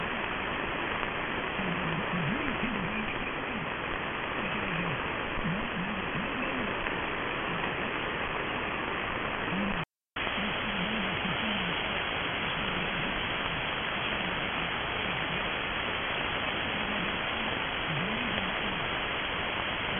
At the time of reception, there was a very weak signal and a challenge for both receivers. The Cloud-IQ renders the signal a little better because there is less background noise. Reception in AMS and 6.7KHz band width.